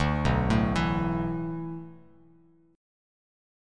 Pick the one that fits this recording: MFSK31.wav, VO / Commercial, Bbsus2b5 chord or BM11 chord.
BM11 chord